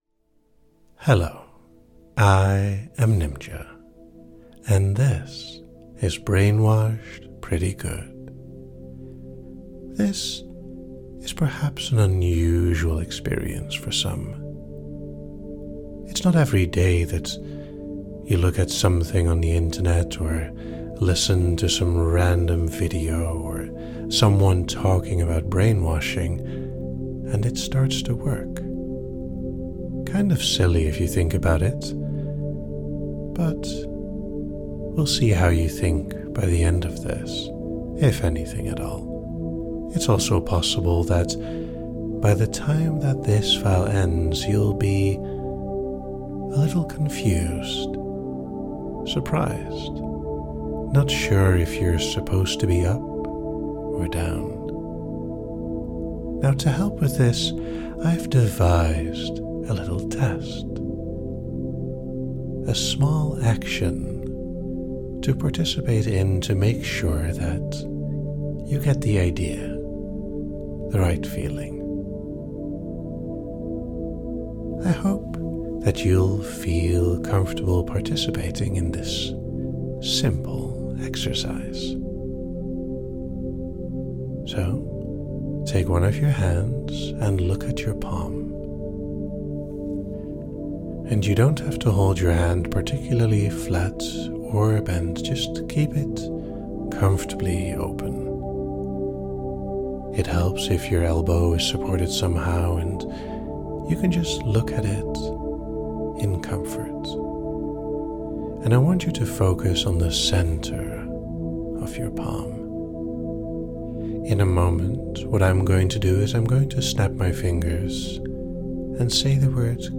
The tone remains playful and light-hearted, encouraging the listener to notice and enjoy the effects of these exercises. Type Gentle Length 18:41 Category Induction Features Fractionation, Participation Like it?